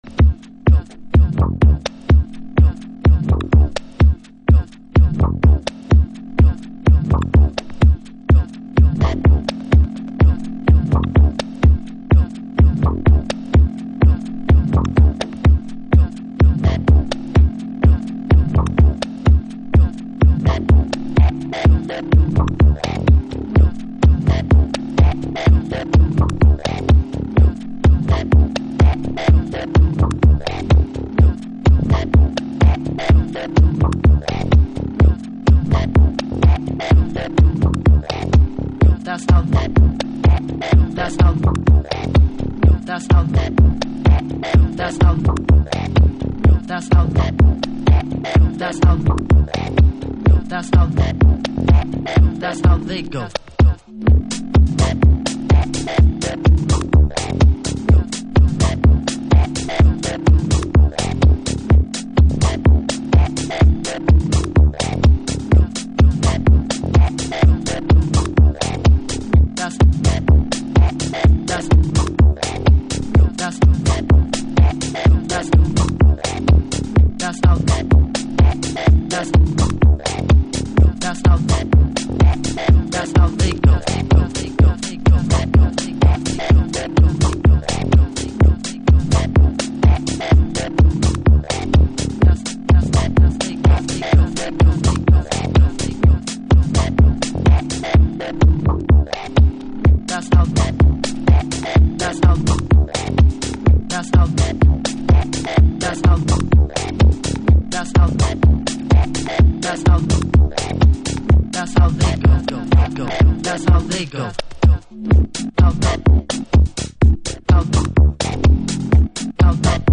Future Jazz / Broken beats